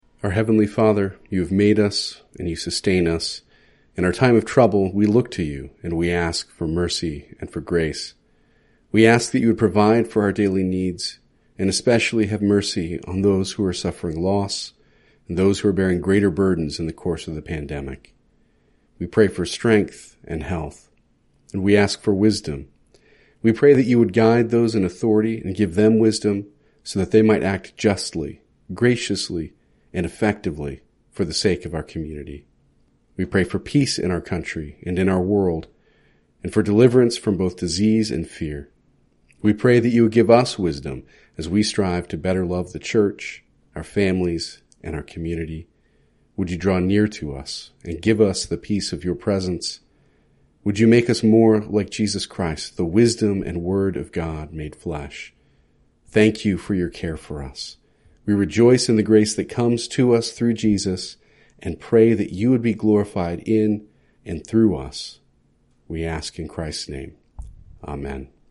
Prayer Time
Congregational-Prayer.mp3